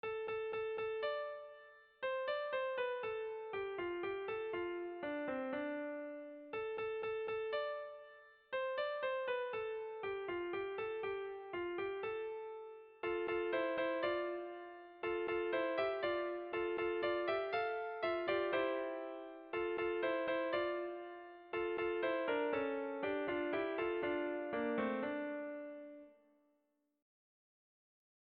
Erlijiozkoa
Zortziko handia (hg) / Lau puntuko handia (ip)
A1A2B1B2